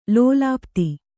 ♪ lōlāpti